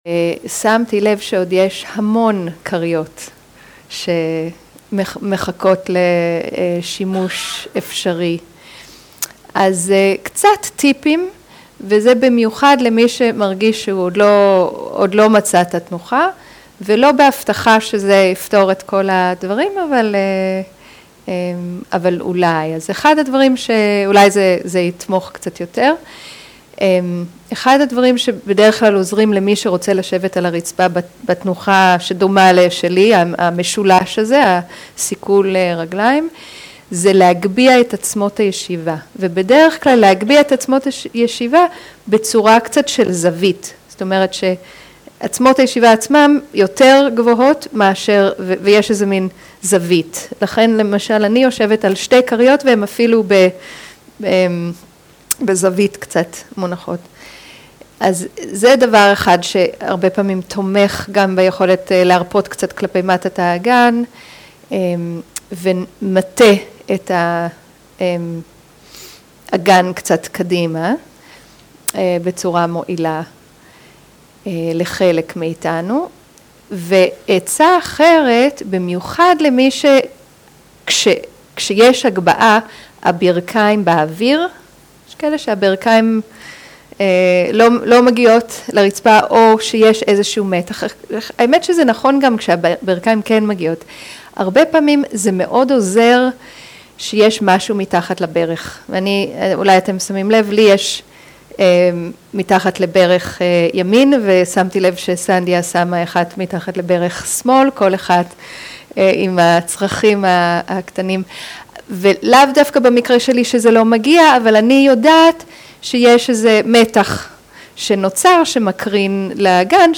שיחת הנחיות למדיטציה
איכות ההקלטה: איכות גבוהה